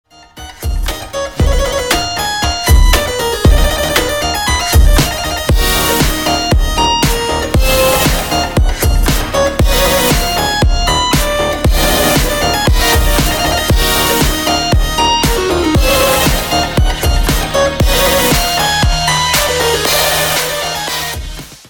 • Качество: 192, Stereo
ритмичные
громкие
Electronic
скрипка
инструментальные
Bass